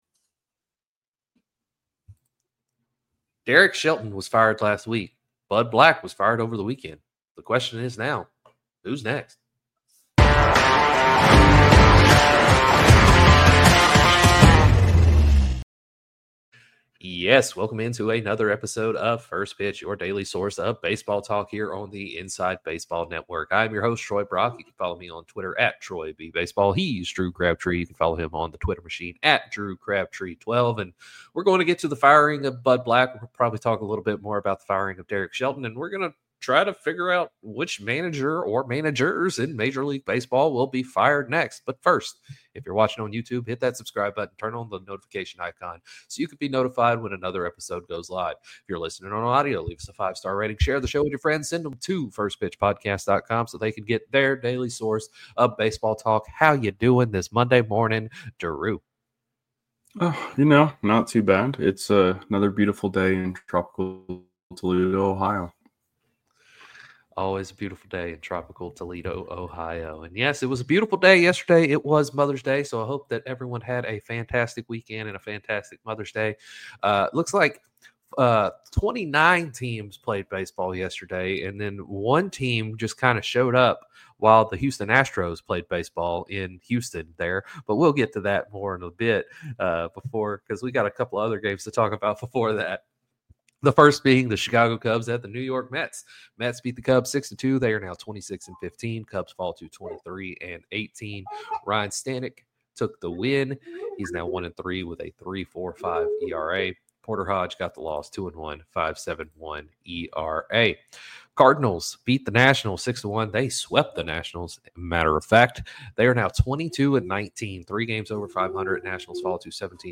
On today's show, the boys discuss Sunday's slate of games before diving into the Colorado Rockies firing Bud Black and who they think the next managers to be fired will be. They close out the show by looking ahead to today's games.